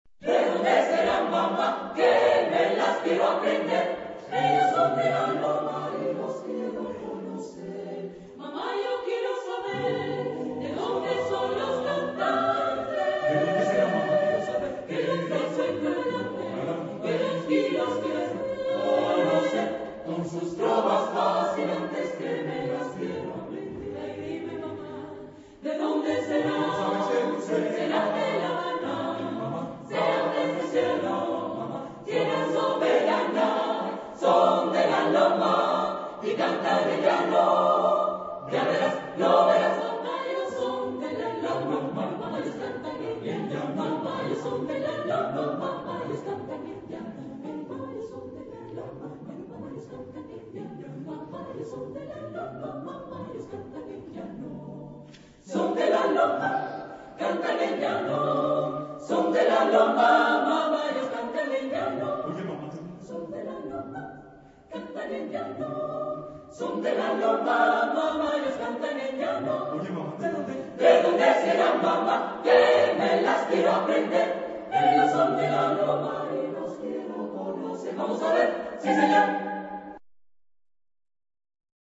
Género/Estilo/Forma: Profano ; Popular ; Danza
Tipo de formación coral: SATBB  (5 voces Coro mixto )
Tonalidad : sol mayor